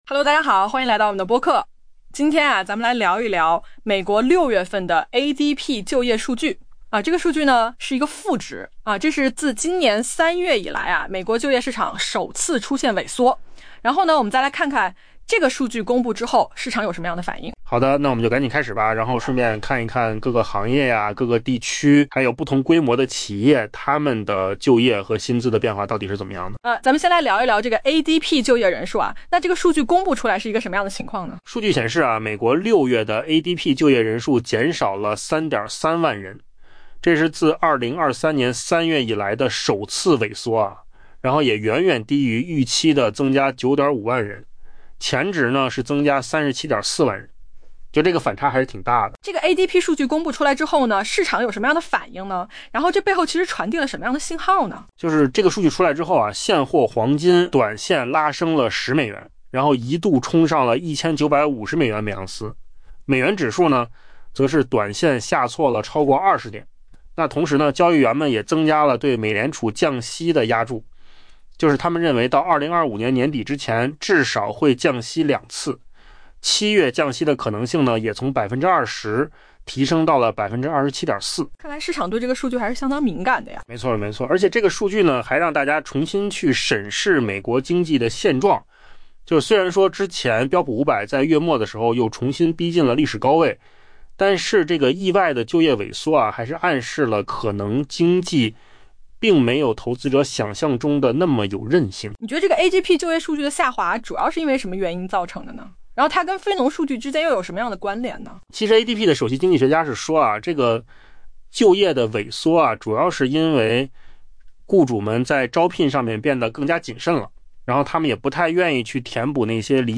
AI播客：换个方式听新闻.mp3 下载mp3
音频由扣子空间生成